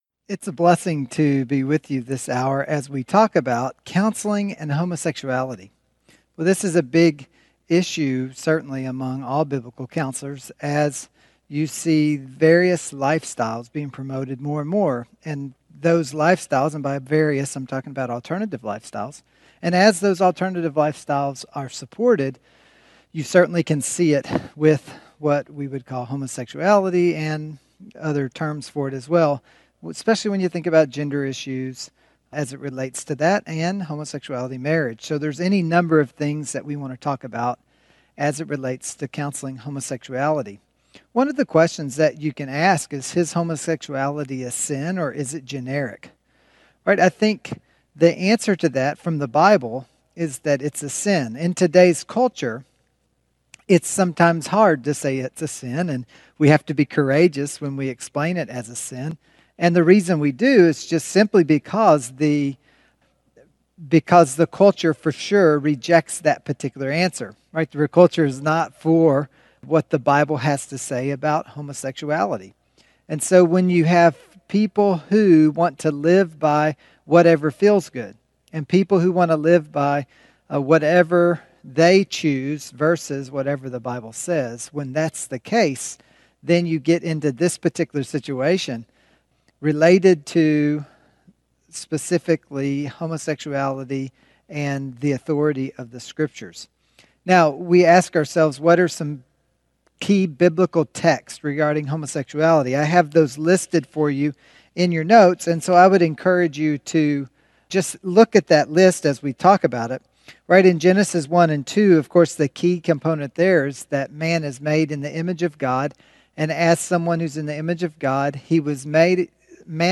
This is a session from a Biblical Counseling Training Conference hosted by Faith Church in Lafayette, Indiana.